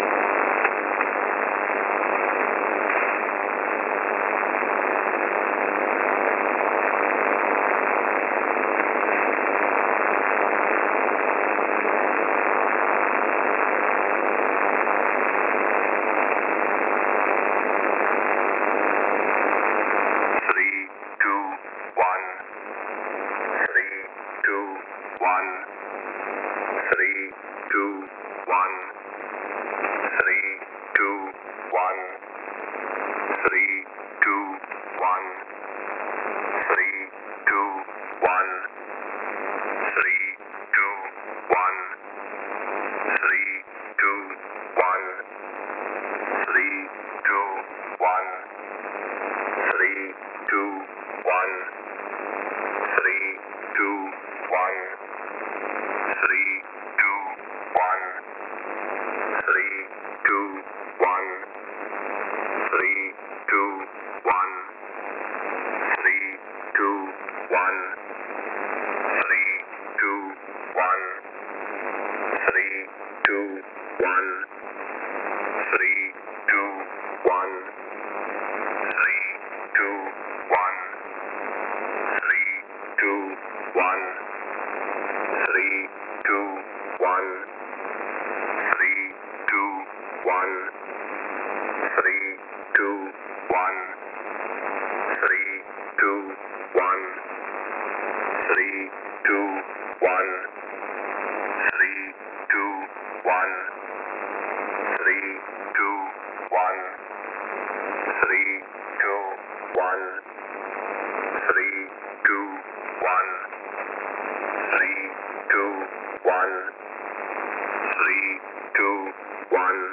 Mode: USB + Carrier Frequency: 4836